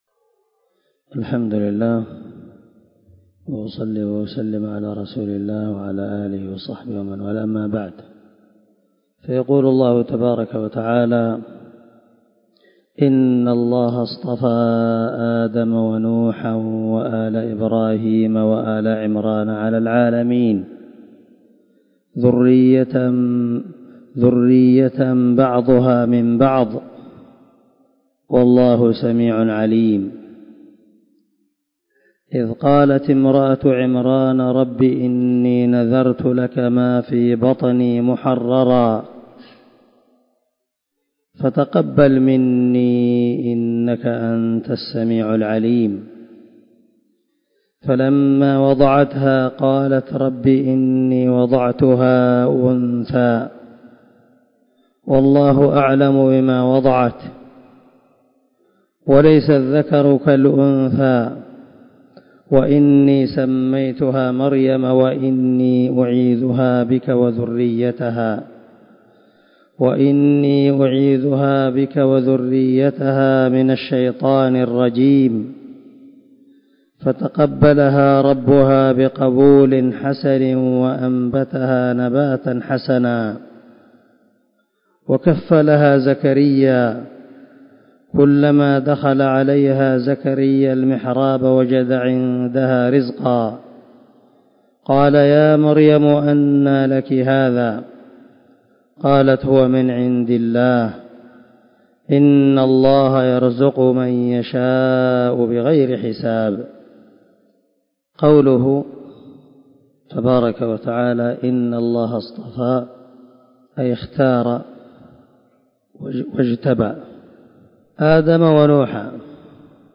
167الدرس 12 تفسير آية( 33 – 37 )من سورة آل عمران من تفسير القران الكريم مع قراءة لتفسير السعدي